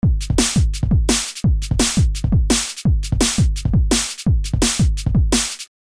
描述：4 bar long loop made in buzz. there are some really low frequencies present, so use hipass filter.
标签： bass jungle loop synth
声道立体声